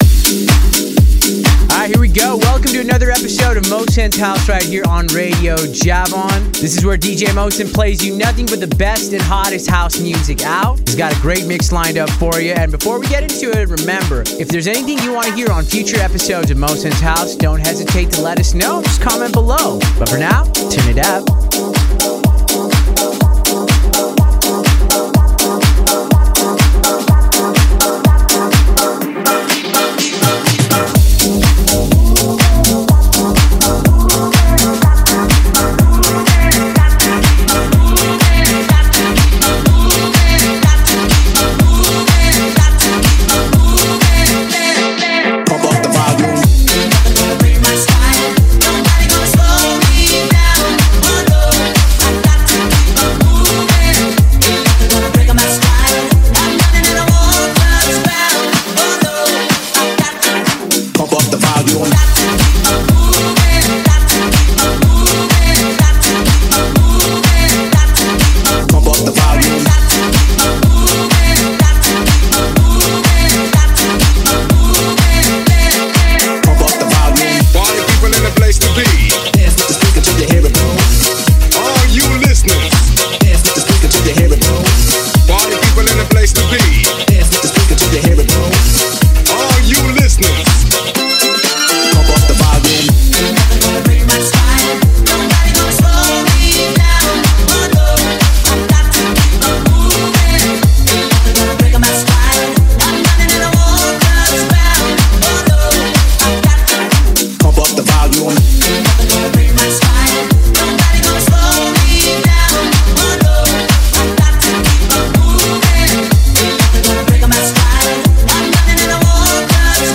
موزیک با کاور اصلی و بدون تگ تبلیغاتی می‌باشد